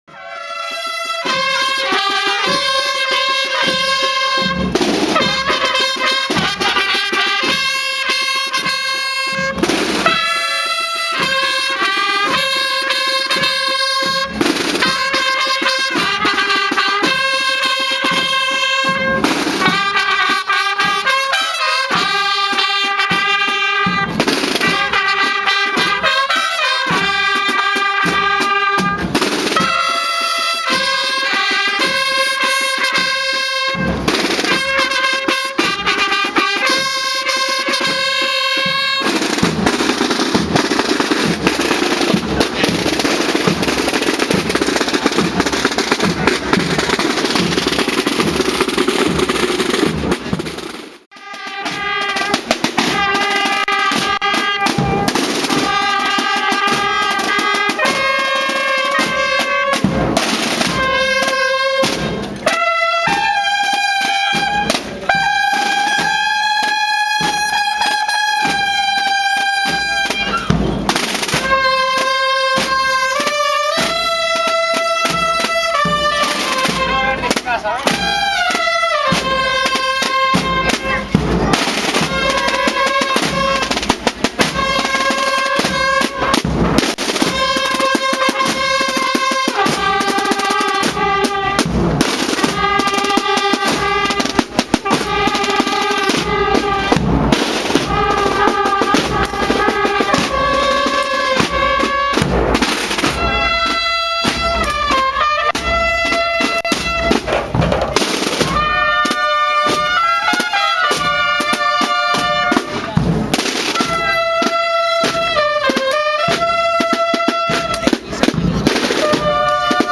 Misa de campaña y Procesión Fiestas de la Paloma 2014
y posteriormente se celebró la procesión que contó con la participación de la banda de cornetas y tambores de la Hermandad de La Negación.